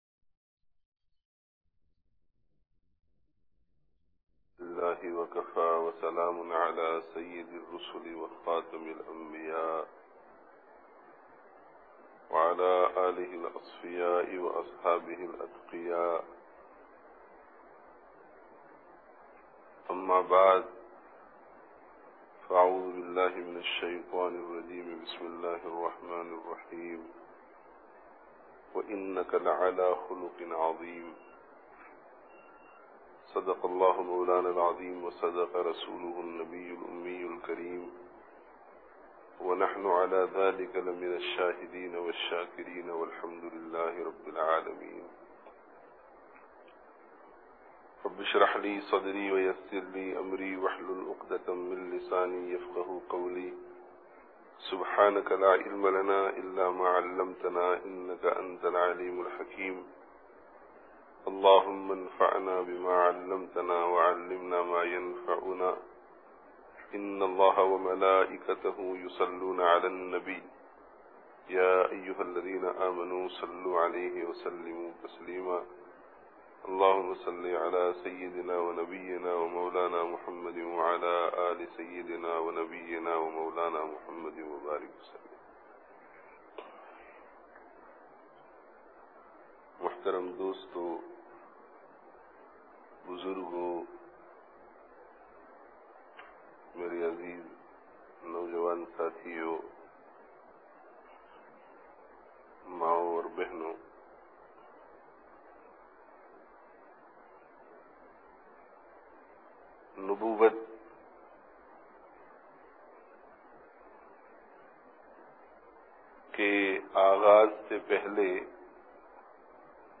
Ghāre Hirā me(n) Wahy kā Āghāz (Masjid An Noor, Leicester 24/11/06)